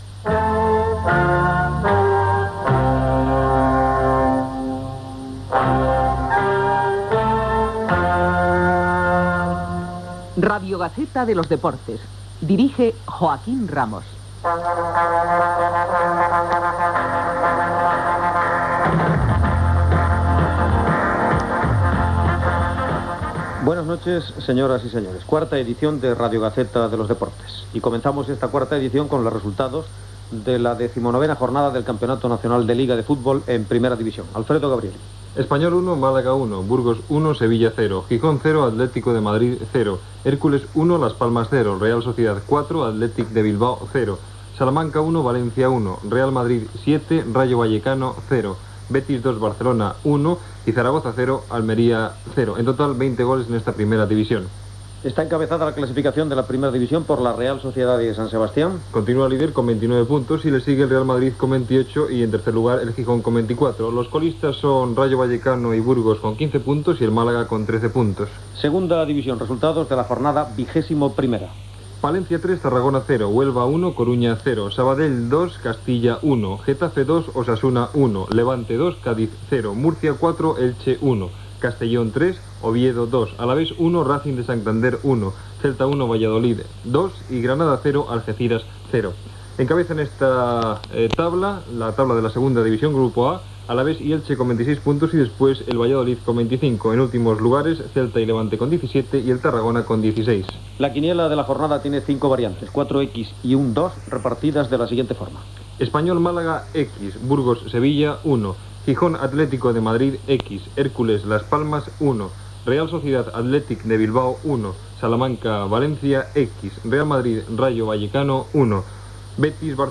Careta del programa, resultats de la primera i segona divisió de la Lliga de futbol masculí, travessa, resultats de la Lliga de segona divisió B, opinions recollides als partits de primera divisió de futbol entre la Real Sociedad i l'Athletic Club i el Real Madrid i Rayo Vallecano
Esportiu